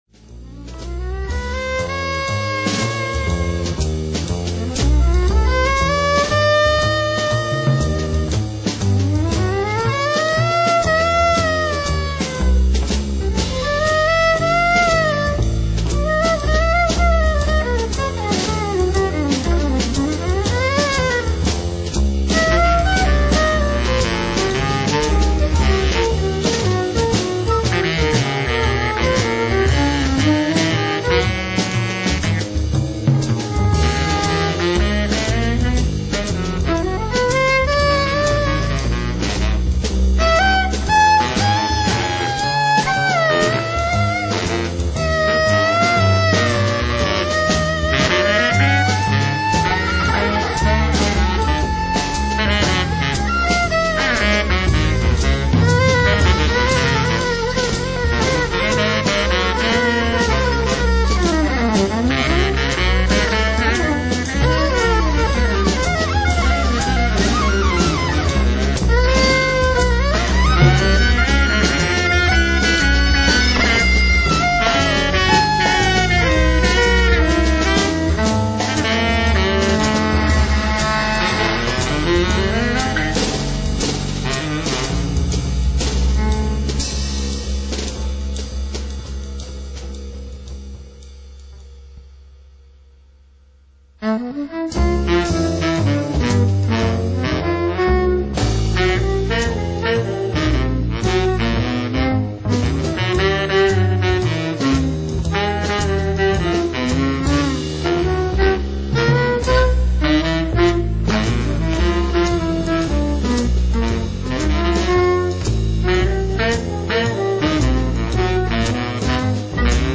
baritone saxofone, tarogato
violin, percussion, flutes
double bass
drum set